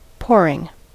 Ääntäminen
Ääntäminen US Haettu sana löytyi näillä lähdekielillä: englanti Käännös Adjektiivit 1. strömend Pouring on sanan pour partisiipin preesens.